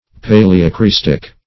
Search Result for " paleocrystic" : The Collaborative International Dictionary of English v.0.48: Paleocrystic \Pa`le*o*crys"tic\, a. [Paleo- + Gr. kry`stallos ice.]
paleocrystic.mp3